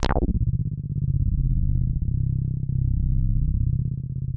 C1_moogy.wav